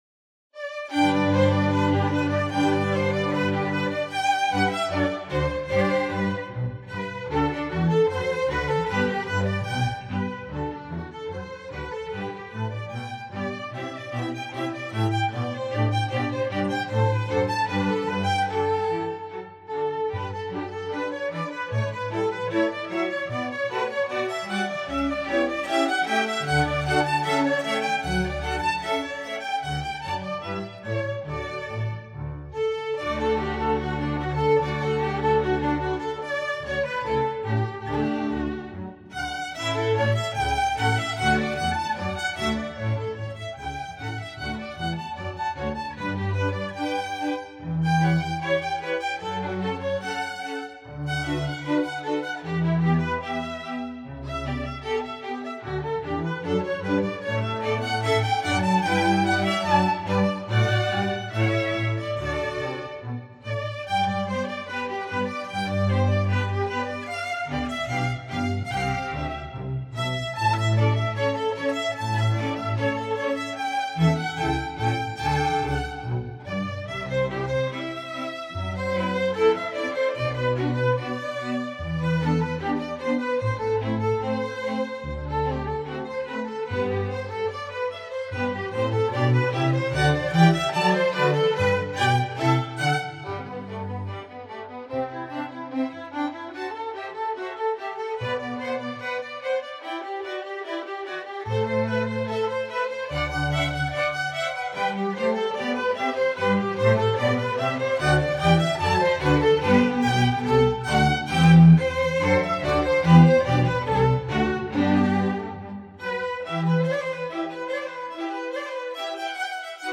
Voicing: String Orchestra S